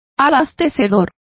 Complete with pronunciation of the translation of caterers.